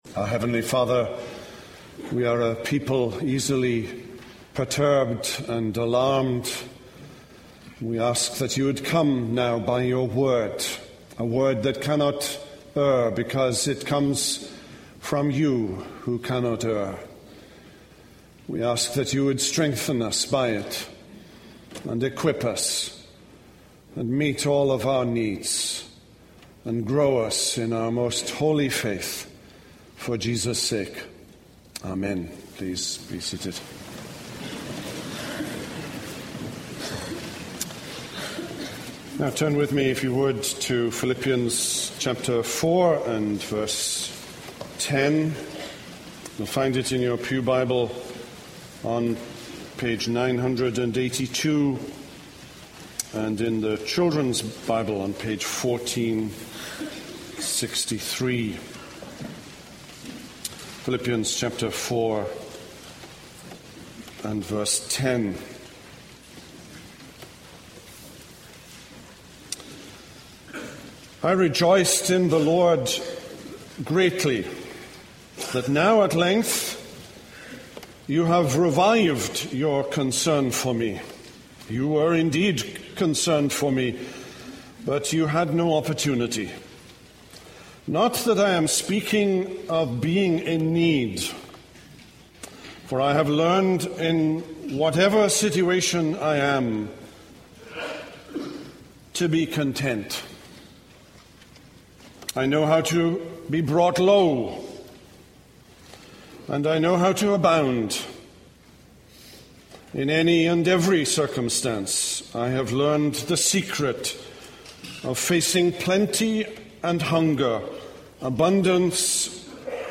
This is a sermon on Philippians 4:10-23.